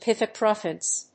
pith・e・can・thro・pus /pìθɪkˈænθrəpəs/
• / pìθɪkˈænθrəpəs(米国英語)